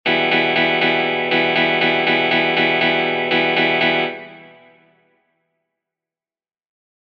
Gitarren Schlagmuster Übung 2:
Bei „Zwei Und“ und „Drei Und“ spielst du Abschlag, Aufschlag dann nochmal Abschlag und Aufschlag.